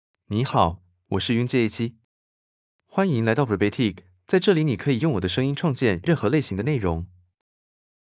YunJhe — Male Chinese (Taiwanese Mandarin, Traditional) AI Voice | TTS, Voice Cloning & Video | Verbatik AI
YunJheMale Chinese AI voice
YunJhe is a male AI voice for Chinese (Taiwanese Mandarin, Traditional).
Voice sample
Listen to YunJhe's male Chinese voice.
Male